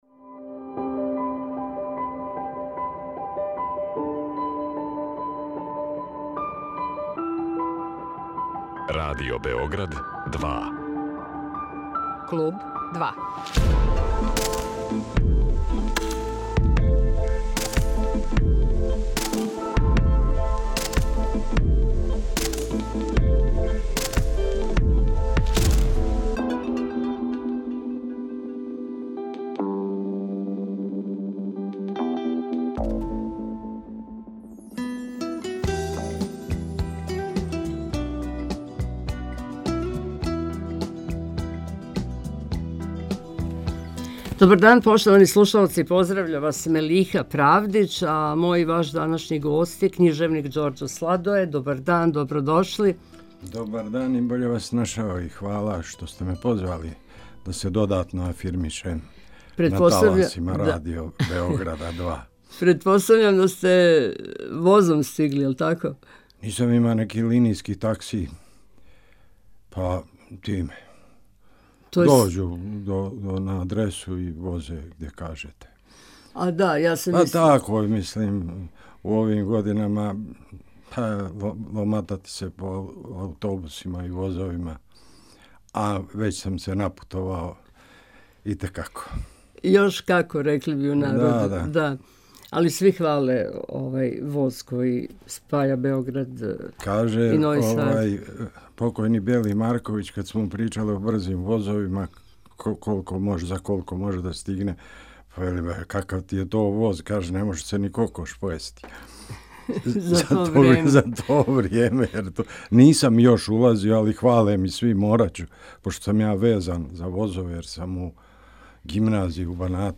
Ове недеље Ђорђо Сладоје је био гост емисије Клуб 2 на Радио Београду 2 и тим поводом је представио своју нову књигу „РИБА ЗВАНА ГАОВИЦА“.